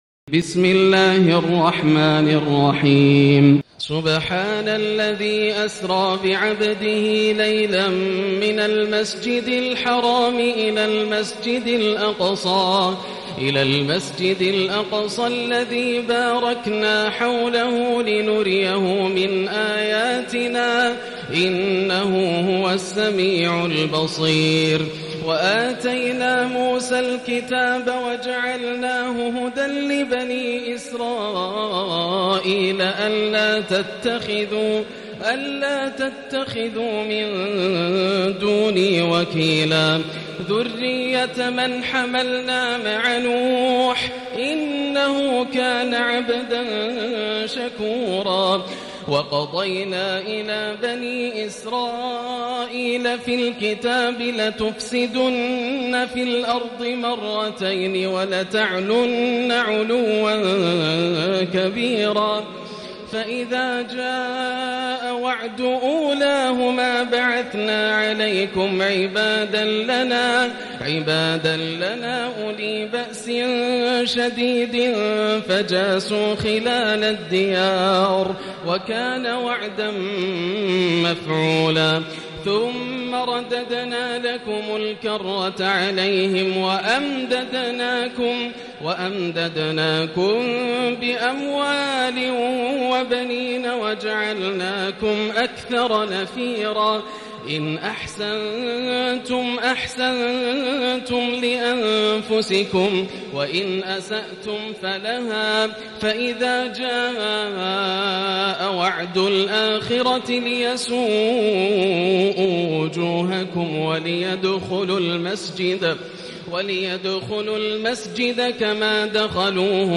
الجزء الخامس عشر > مصحف الشيخ ياسر الدوسري (مصحف الأجزاء) > المصحف - تلاوات ياسر الدوسري